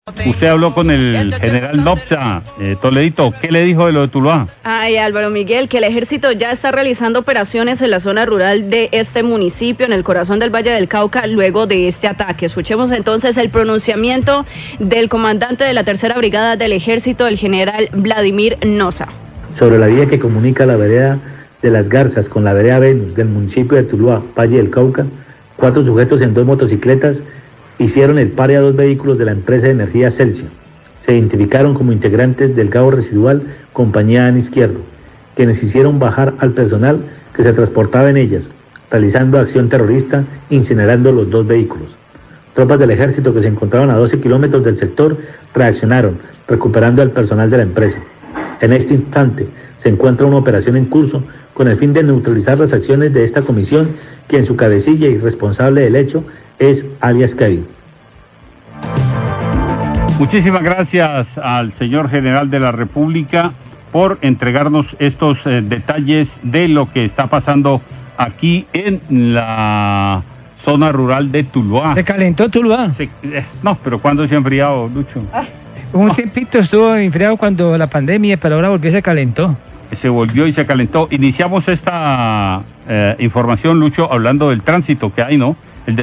Comandante del Ejército se refirió a la presencia de disidencias de las Farc, Radio Calidad 1218pm